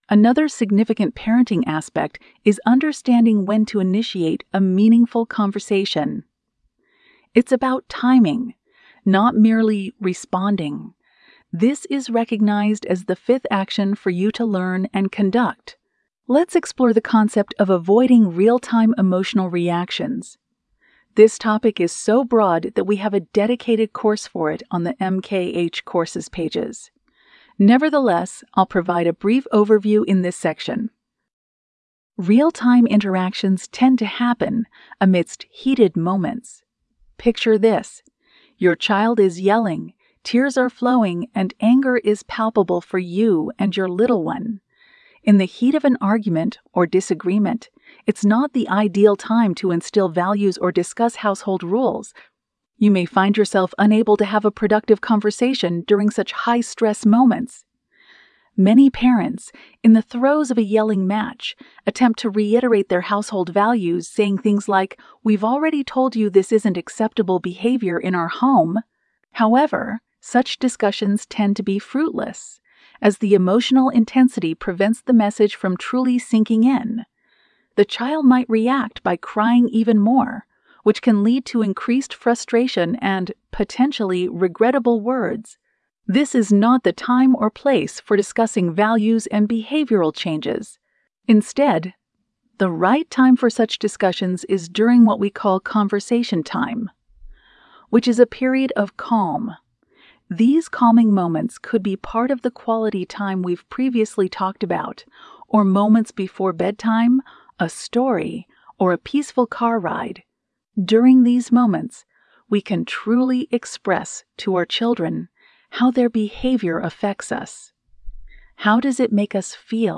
Lecture 6: Build a Healthy Parent-Child Relationship-Part2